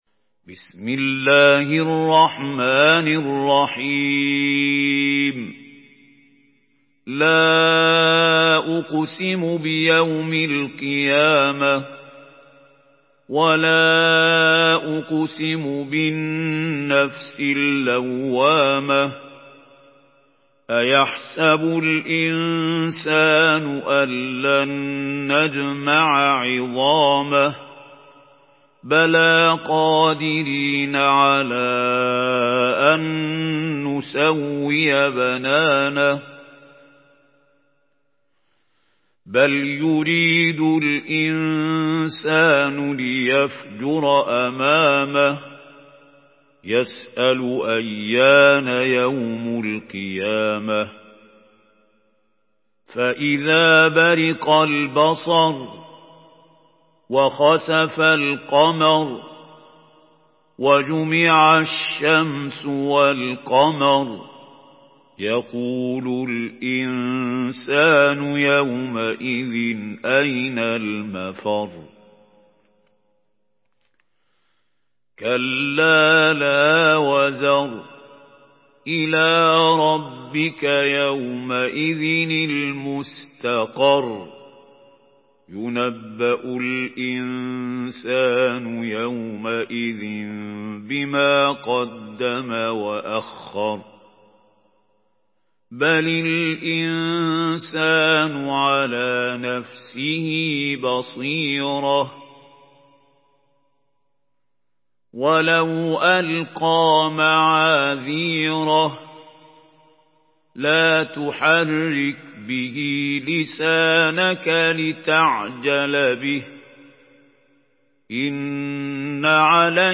Surah القيامه MP3 by محمود خليل الحصري in حفص عن عاصم narration.
مرتل